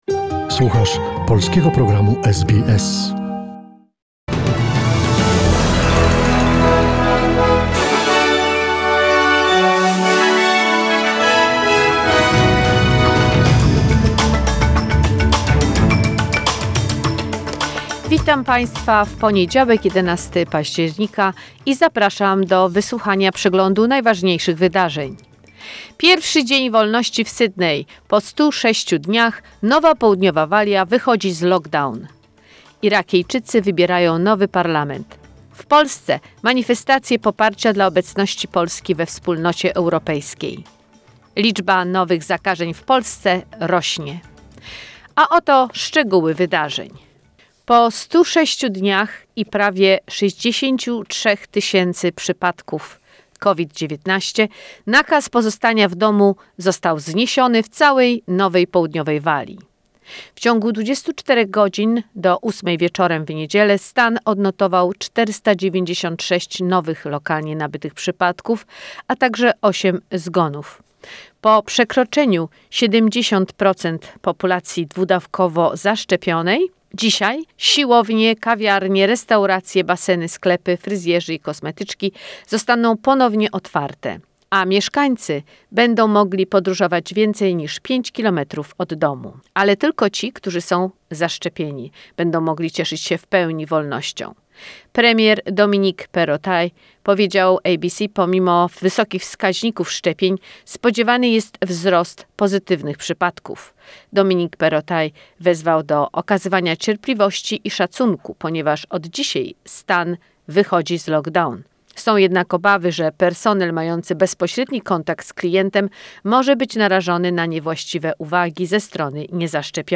SBS News in Polish, 11 October 2021